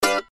m_match_guitar.ogg